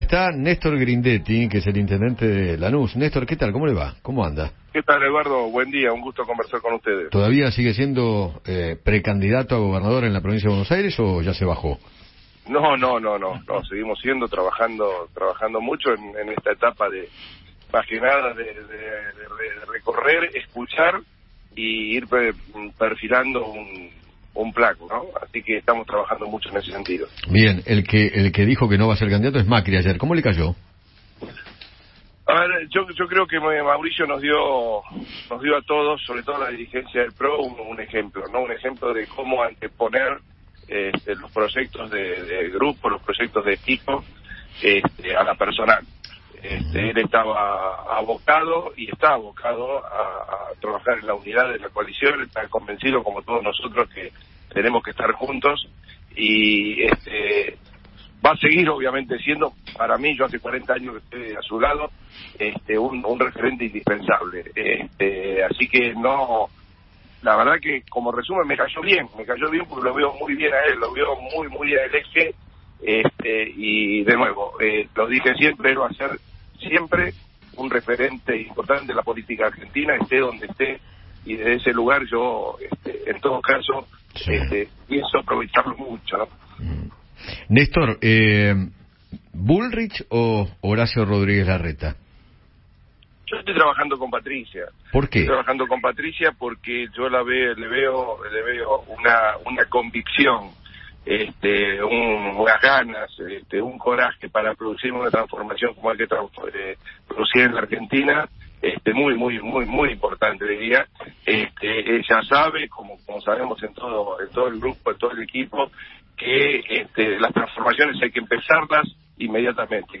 Néstor Grindetti, intendente de Lanús, conversó con Eduardo Feinmann sobre las razones por las cuales apoya a Patricia Bullrich en su carrera presidencial.